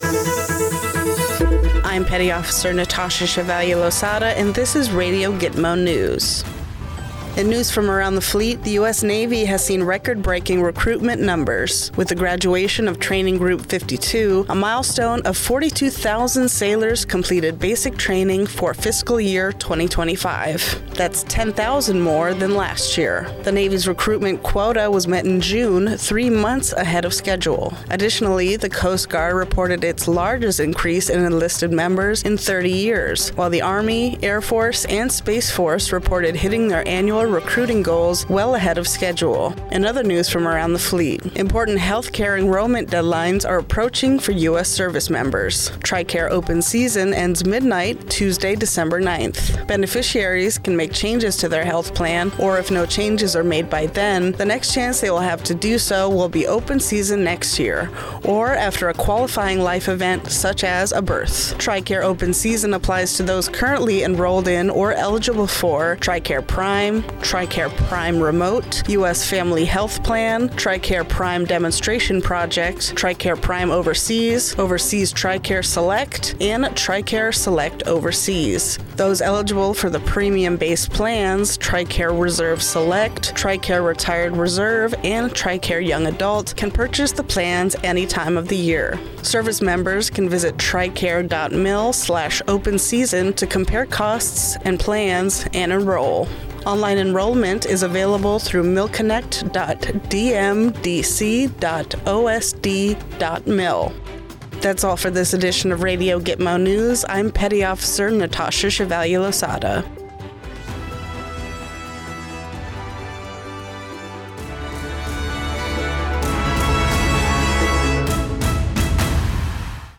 Radio News - Record Bootcamp Graduation/Tricare Enrollment Period